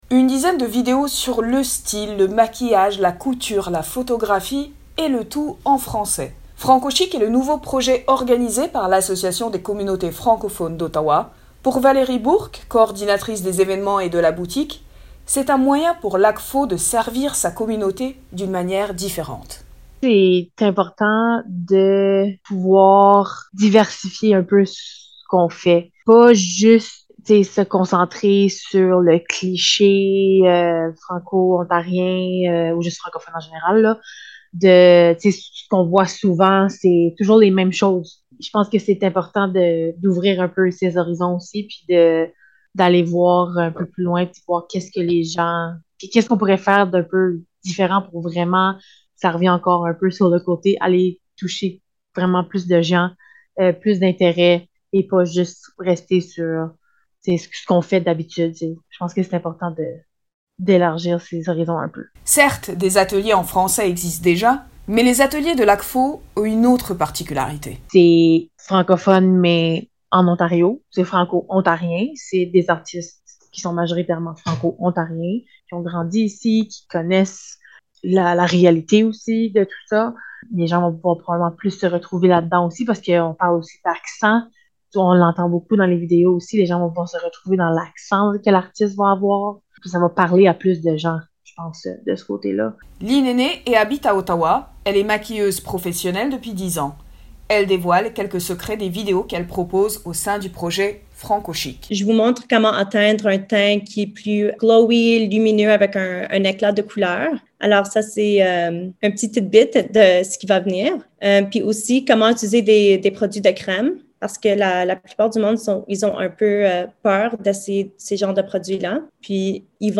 Reportage-Franco-Chic-Reportage-IJL.mp3